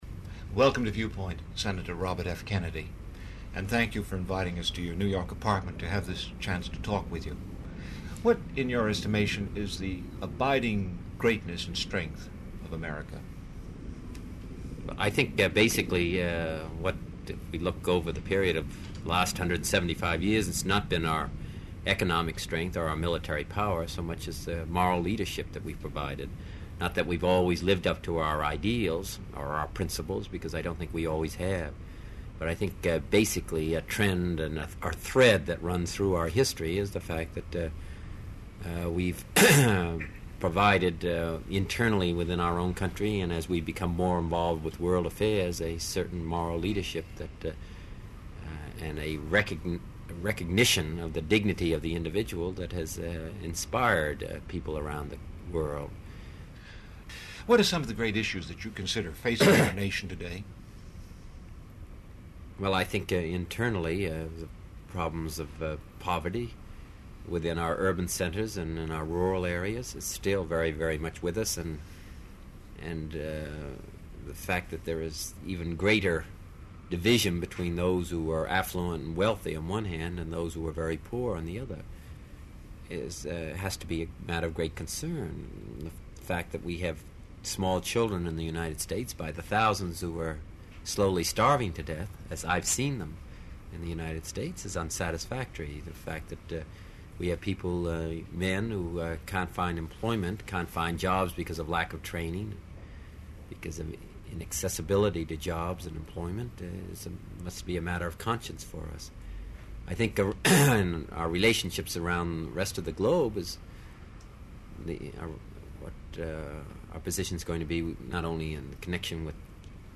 Senator Robert F. Kennedy reflects upon the disparity between America’s moral leadership in the world and the very real problems of inequity, poverty, and racism within America itself, 1968.